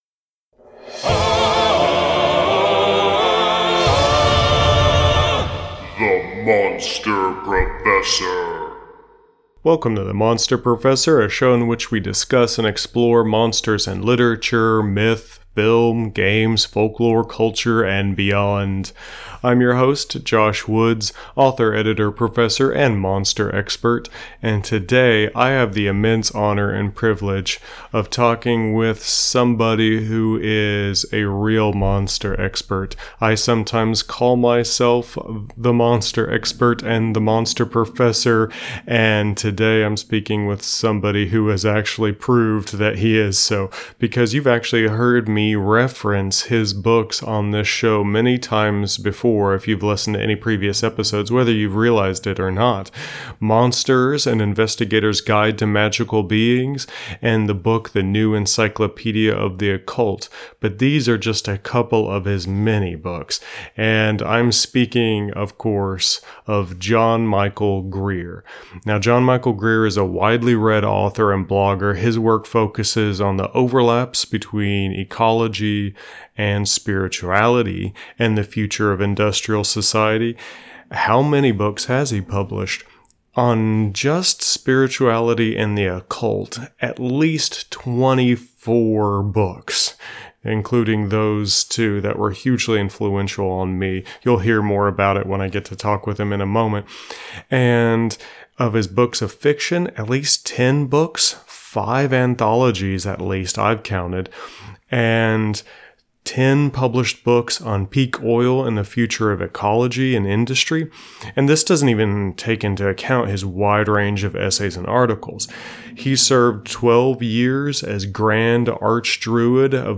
conversation-with-john-michael-greer.mp3